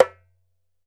ASHIKO 4 0OR.wav